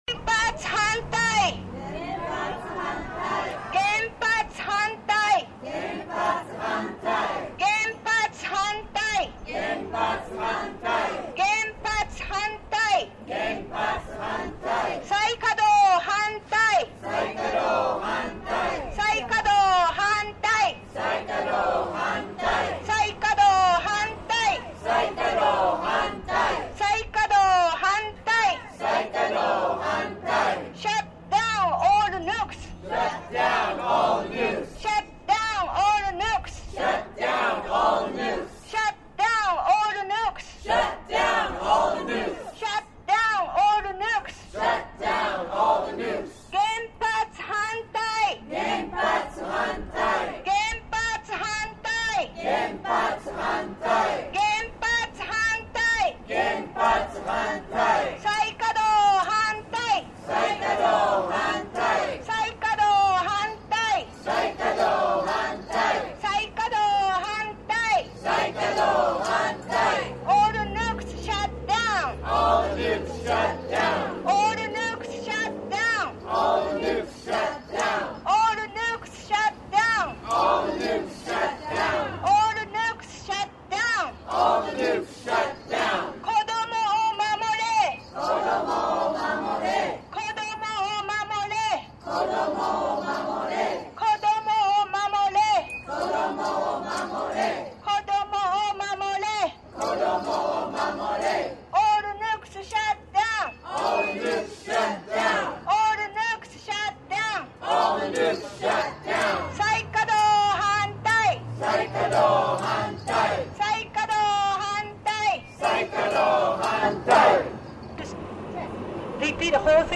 Hear audio from a protest several weeks ago in front of the Japanese consulate on Market Street. Two bilingual Japanese visitors speak through a megaphone on the state of affairs in Japan.